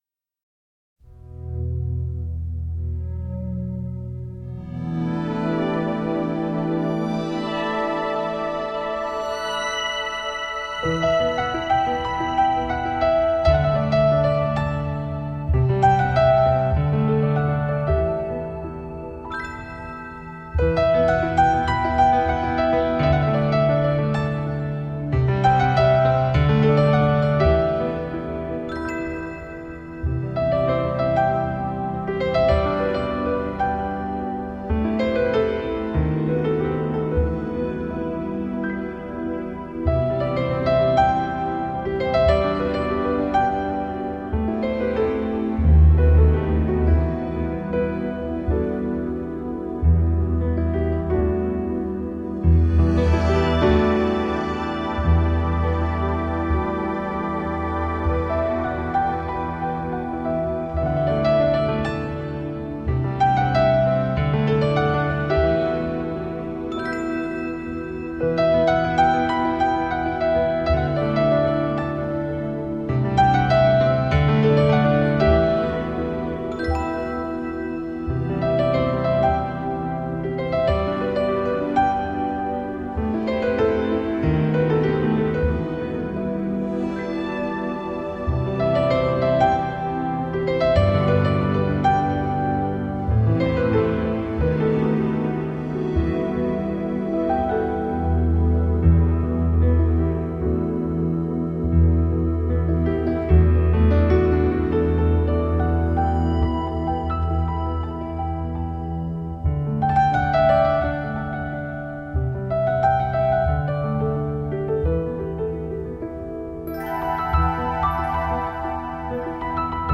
他那悠扬自如的指功，不但呈现华丽多彩的巴洛克风格，还多了份南加州典雅的节奏感。
分别以巴洛克与新世纪两种风格